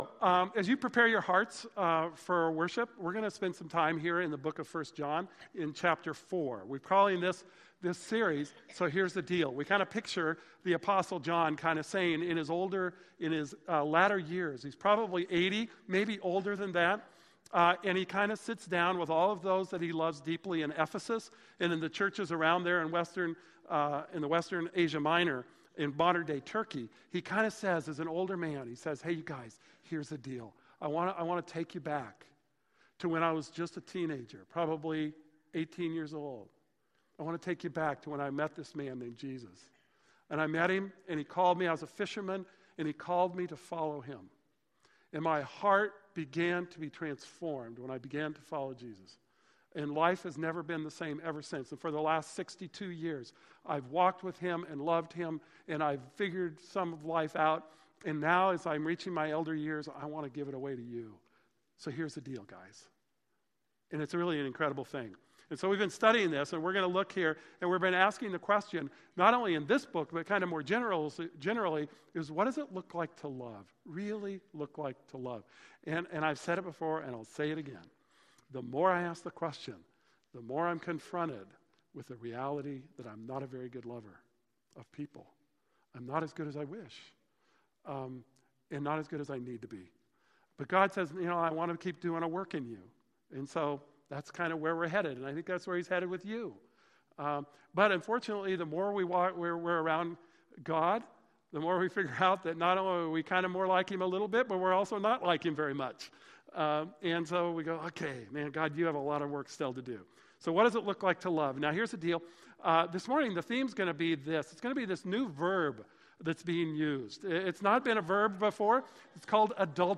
This message is out of 1 John 4:1-12. There was a video clip used from Big Daddy, but due to copywriting, we cannot include the clip in the recording.